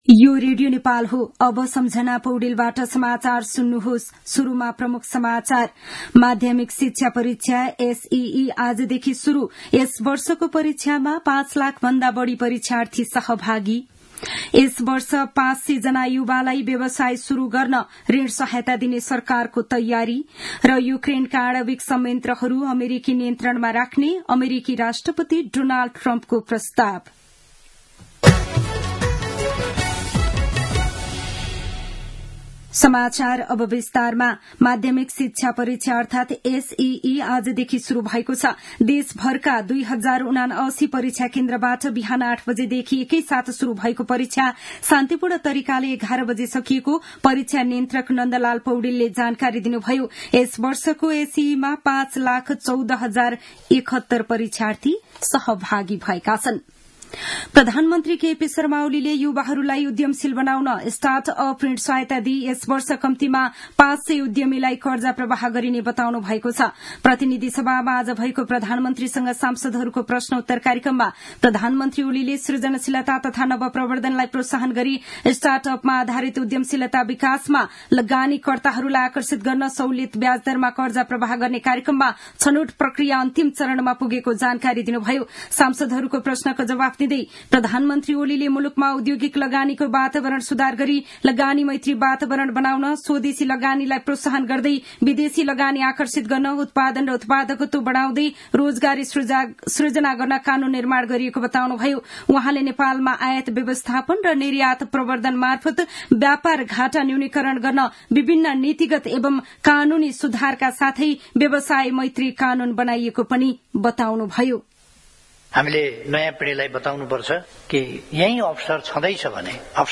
दिउँसो ३ बजेको नेपाली समाचार : ७ चैत , २०८१
3-pm-news-1-6.mp3